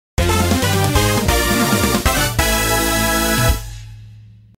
The sound that plays when finishing a race in 1st place